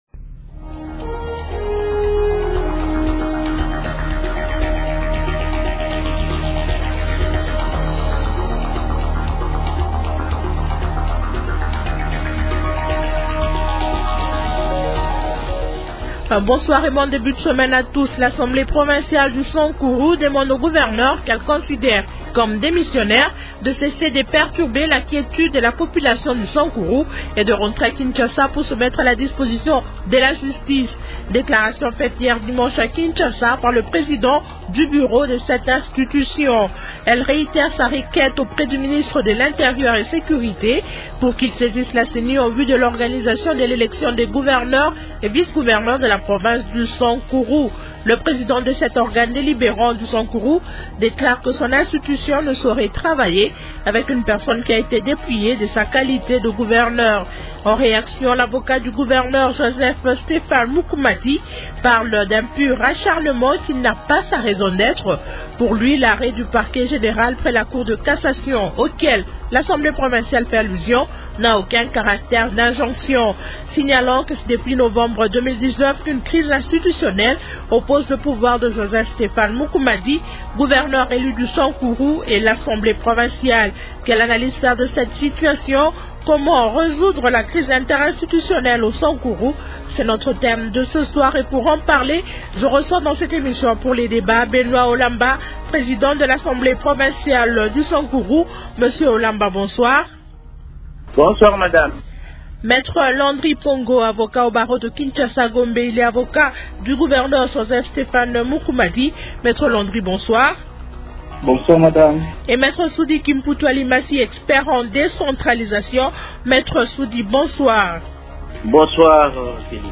-Comment résoudre la crise interinstitutionnelle au Sankuru ? Invités : -Benoit Olamba, Président de l’Assemblée provinciale du Sankuru.
Expert en décentralisation.